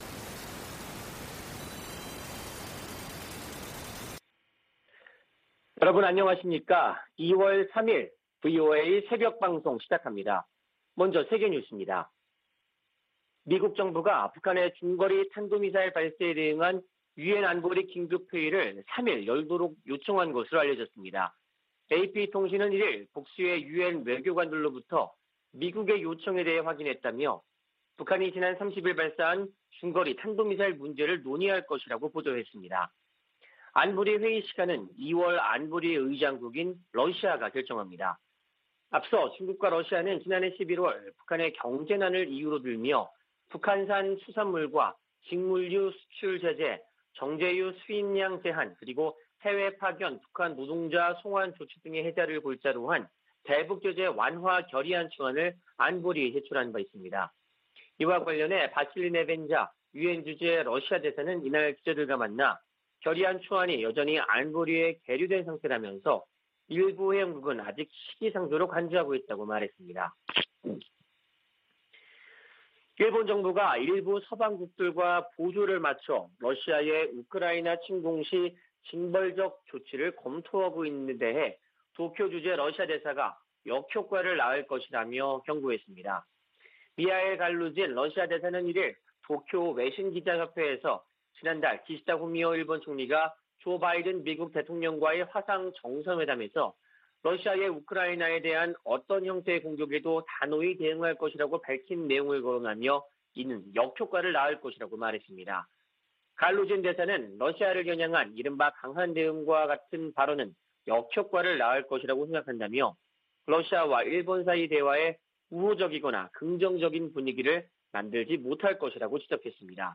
VOA 한국어 '출발 뉴스 쇼', 2021년 2월 3일 방송입니다. 미 국무부 대북특별대표가 한일 북 핵 수석대표들과 북한의 최근 중거리 탄도미사일 발사를 논의하며 도발을 규탄했습니다. 미국이 북한 미사일 발사에 대응한 유엔 안보리 긴급 회의를 요청했습니다. 유엔이 북한의 중거리 탄도미사일 발사를 모라토리엄 파기로 규정했습니다.